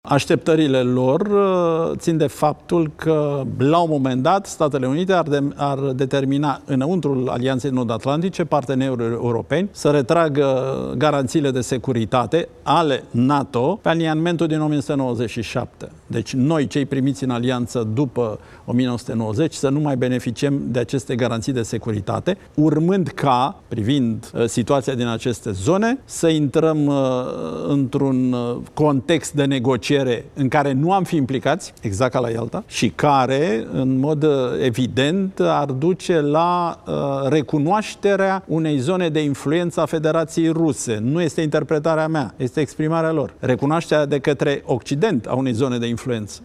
Consilierul prezidențial pentru apărare și siguranță națională, Cristian Diaconesu susține într-un interviu acordat postului Antena 3 că partea americană a „refuzat, în acest moment, dar nu avem garanții”.